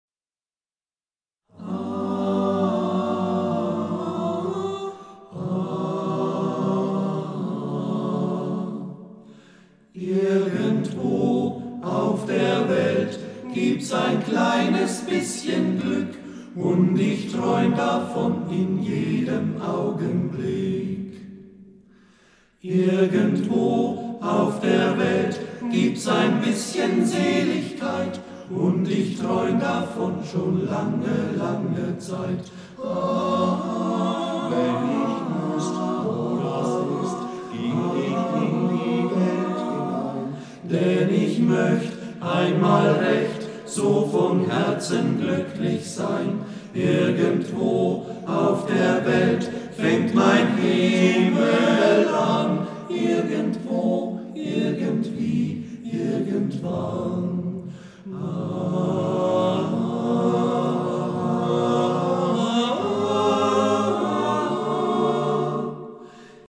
A-capella-Chorgesang
Keine Klavierbegleitung und schon gar kein Halbplayback.